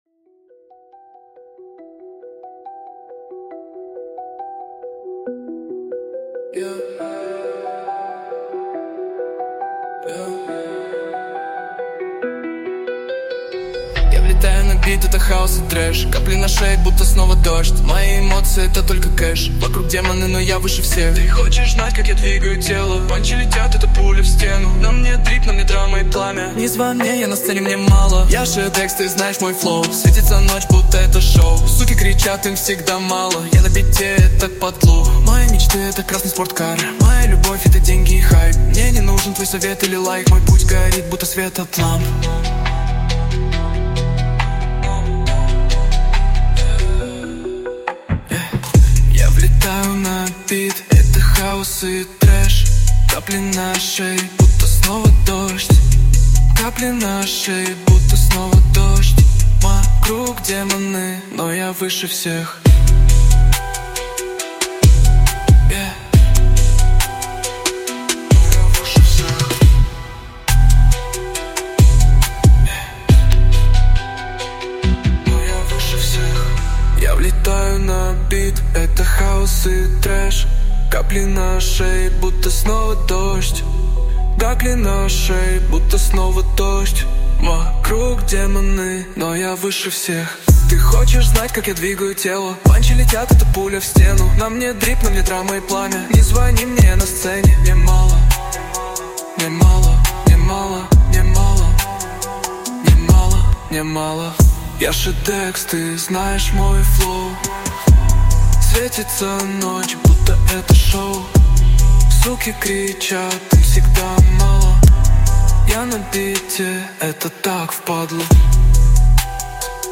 Жанр: Hip Hop